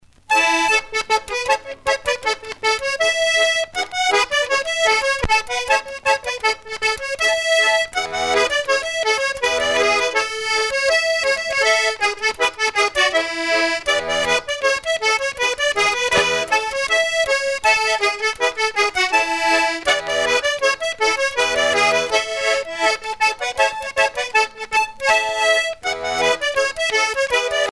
danse : scottich trois pas
Pièce musicale éditée